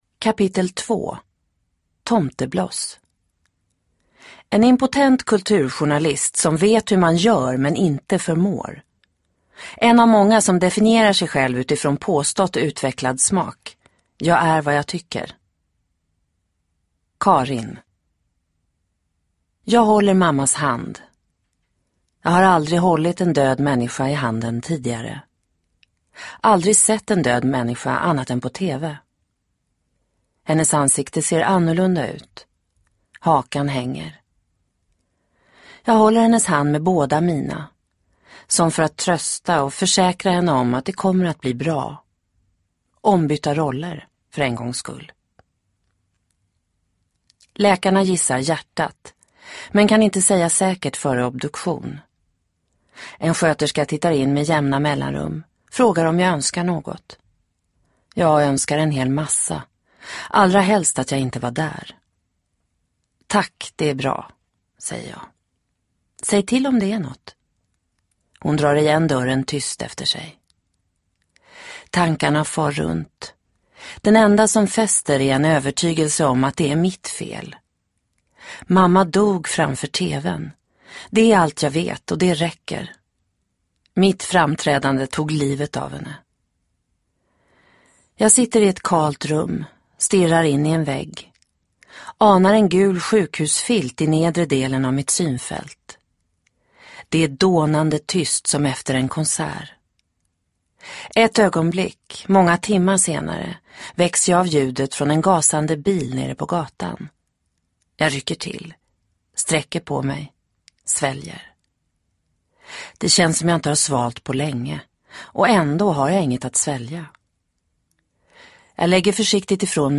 Hemvändaren del 2 – Ljudbok – Digibok